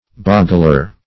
boggler - definition of boggler - synonyms, pronunciation, spelling from Free Dictionary Search Result for " boggler" : The Collaborative International Dictionary of English v.0.48: Boggler \Bog"gler\, n. One who boggles.